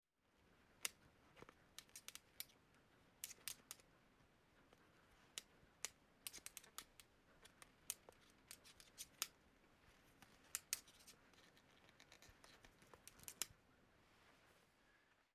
Звуки стетоскопа
Стук стетоскопа в ладони